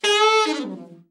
ALT FALL  10.wav